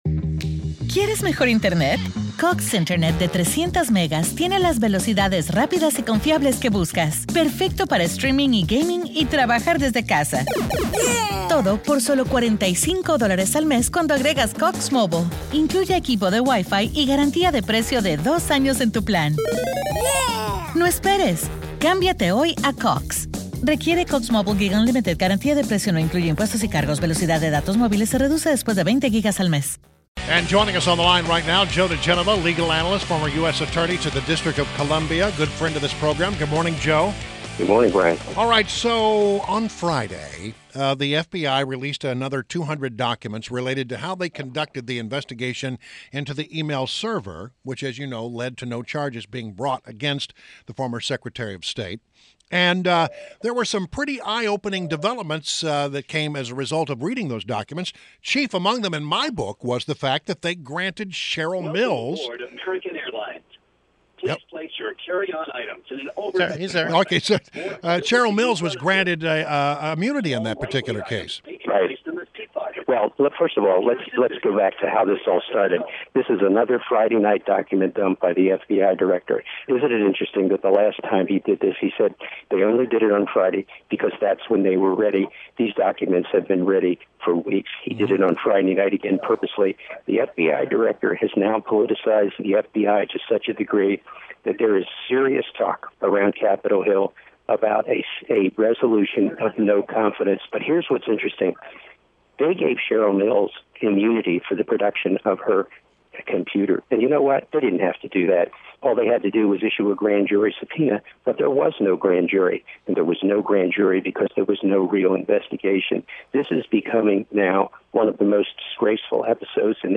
WMAL Interview - JOE DIGENOVA - 09.26.16
INTERVIEW – JOE DIGENOVA – legal analyst and former U.S. Attorney to The District of Columbia